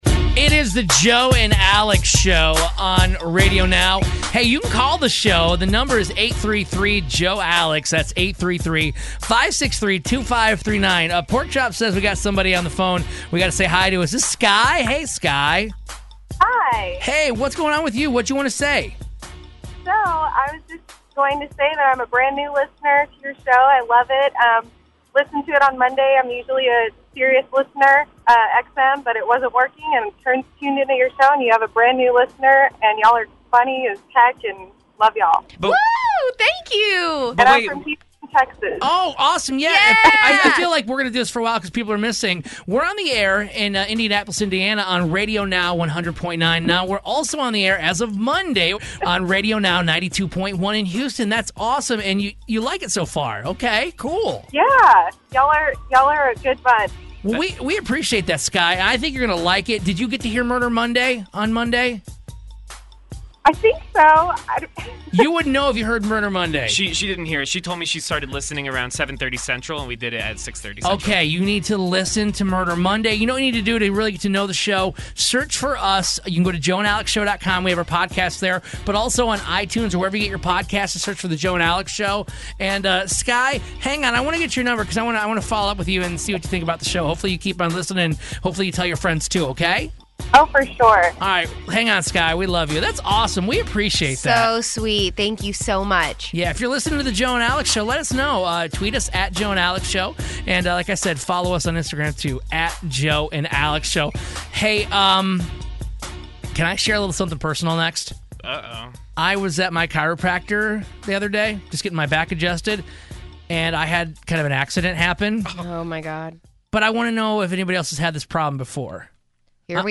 Our First Houston Caller
Now that the show is being streamed live into Houston we got our very first Houston caller!